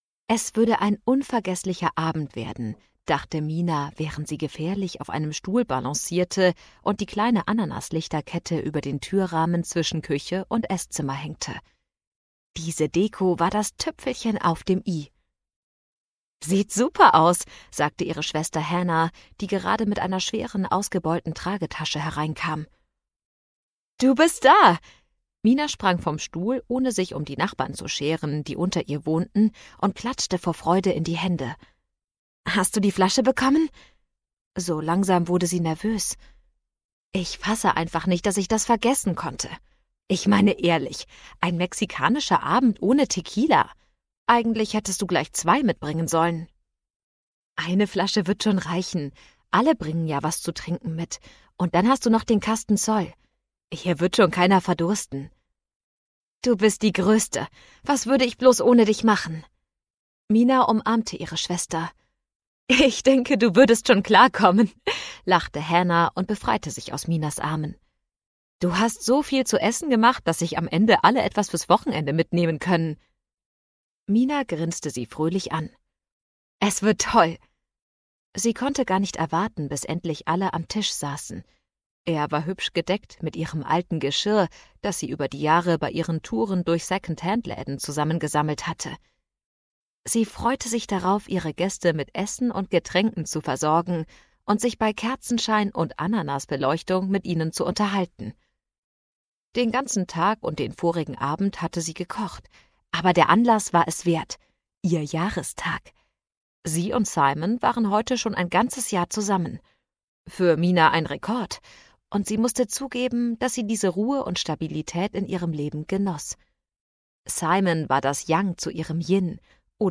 2021 | Ungekürzte Lesung